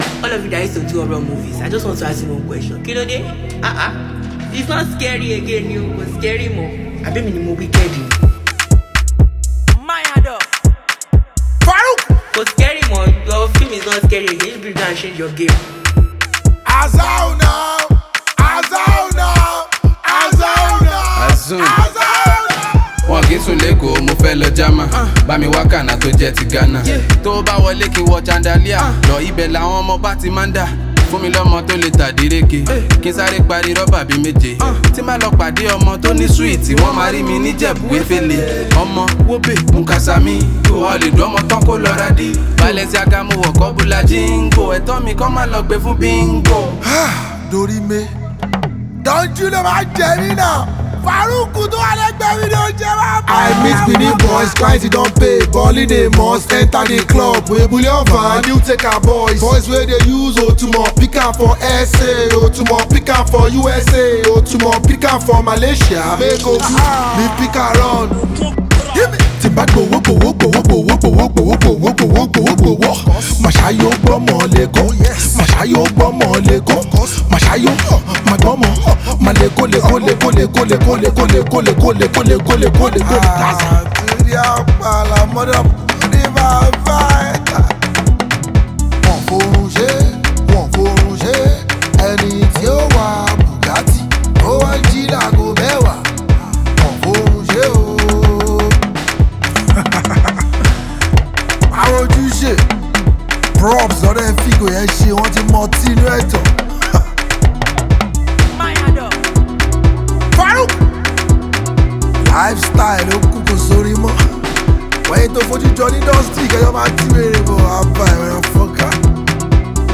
smooth Afrobeat rhythms with heartfelt emotions